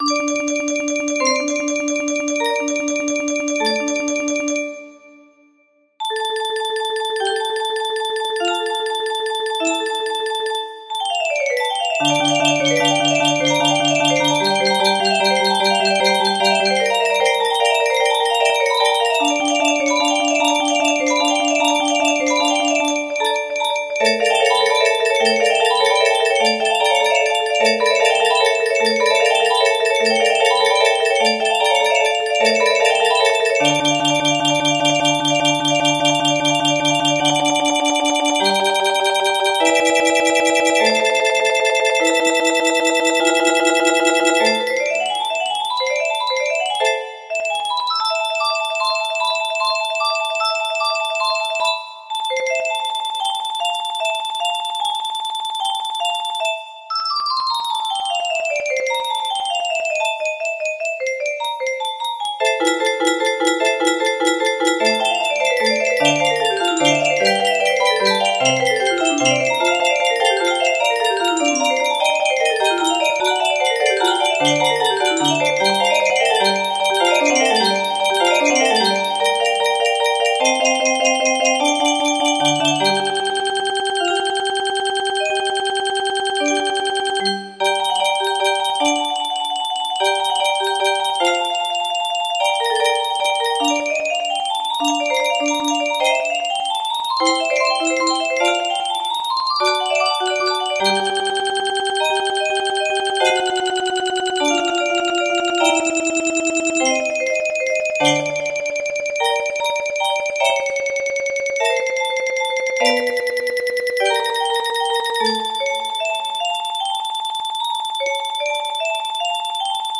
VIVALDI SUMMER - ALLEGRO music box melody